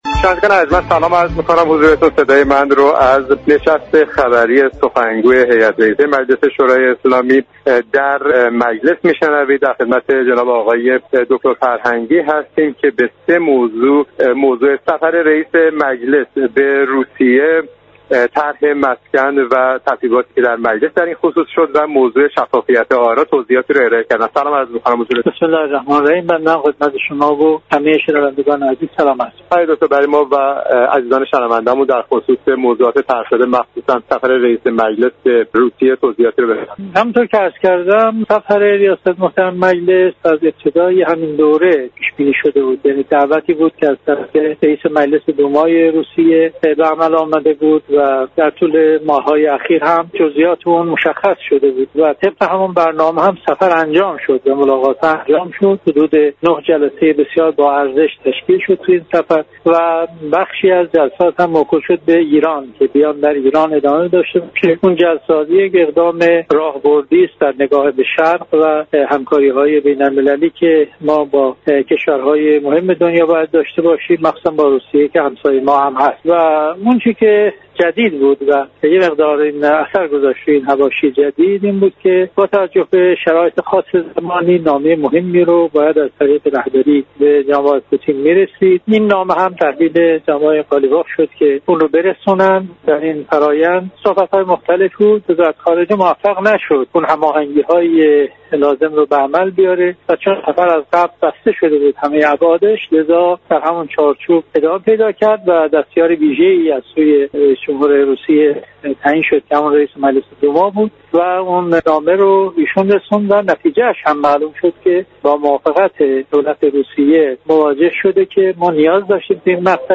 گفت و گوی اختصاصی